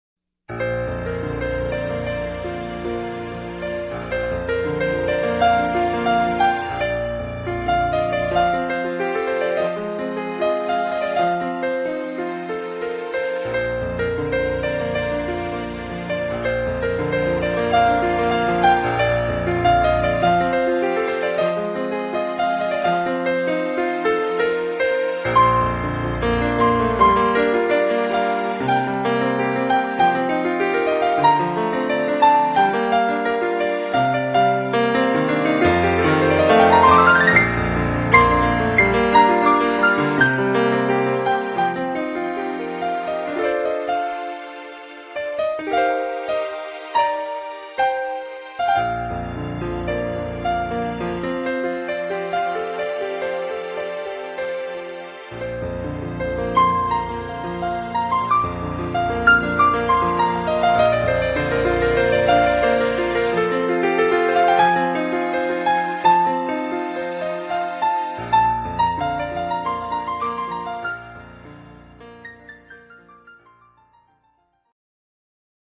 Solo Piano / Keyboard:
Showtune